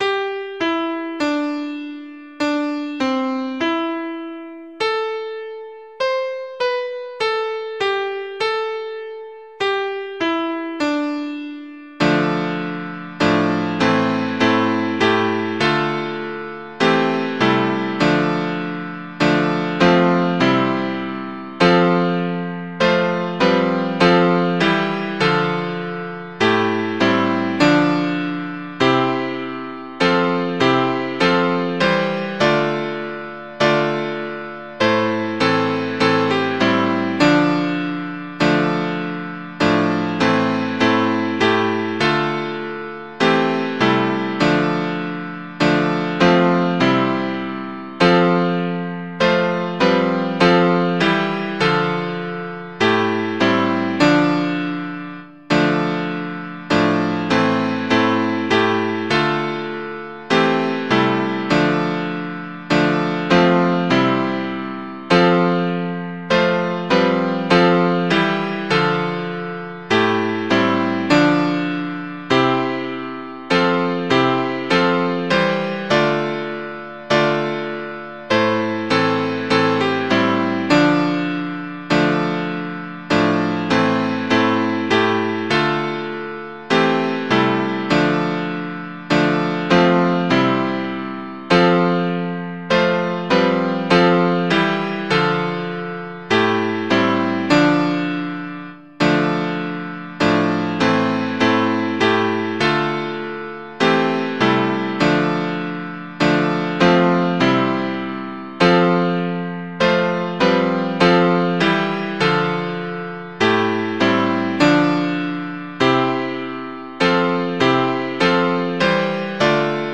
Music: 'Wondrous Love' American Folk Tune.
Mp3 Audio of Tune Abc source